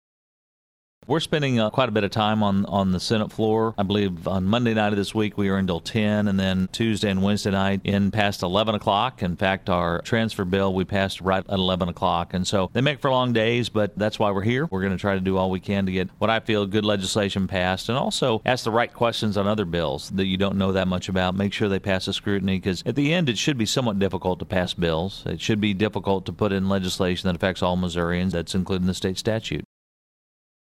The following audio comes from the above interview with Sen. Pearce, for the week of May 4, 2015.